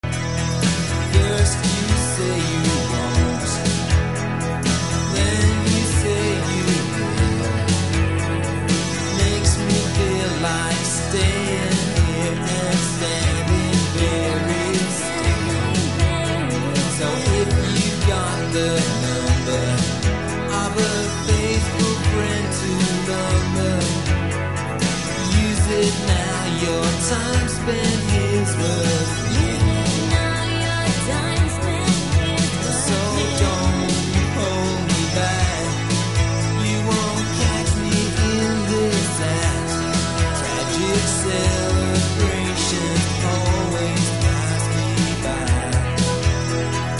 Recorded at La Maison and Trackdown studios.